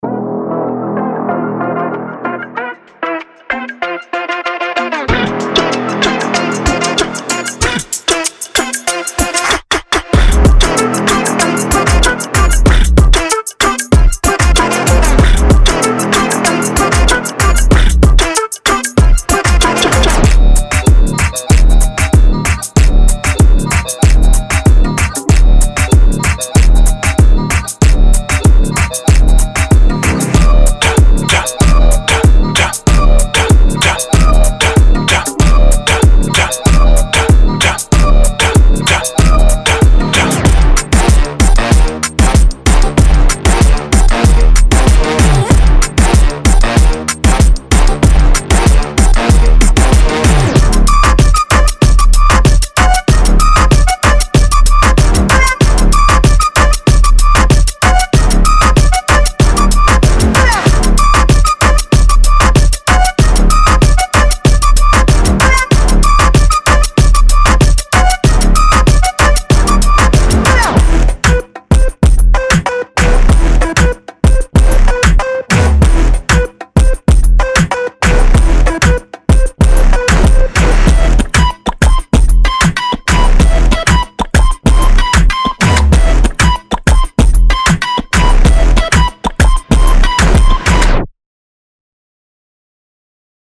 Genre:Latin
数分でエネルギッシュでダンスフロア向けのトラックを構築できる、シンプルで使いやすいパックです。
デモサウンドはコチラ↓
40 Drum Loops
8 Brass Loops
3 Flute Loops
5 Vocal Loops